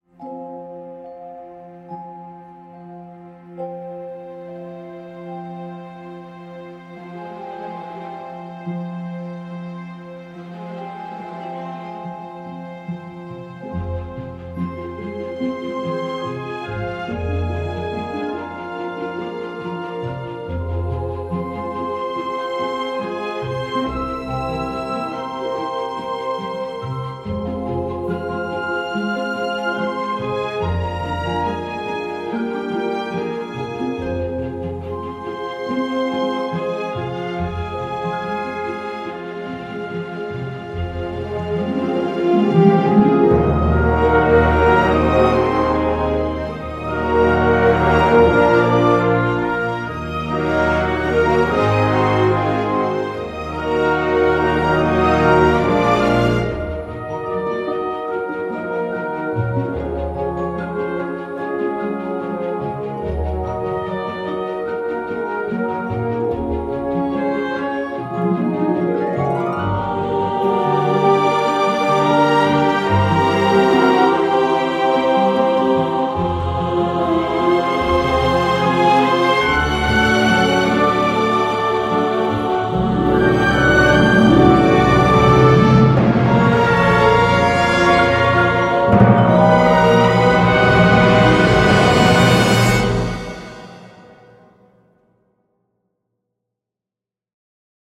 Ambiance magique oblige